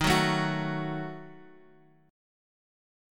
Ebdim chord